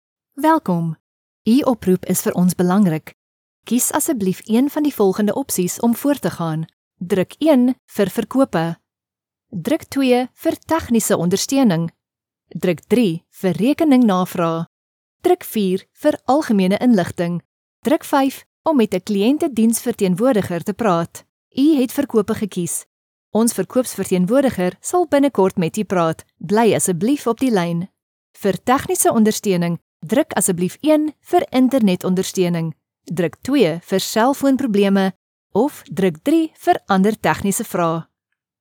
IVR
Meine Stimme kann zugänglich und freundlich, bestimmend, warm und glaubwürdig oder auch schrullig und lebhaft sein.
Schallisolierter Raum
HochMezzosopran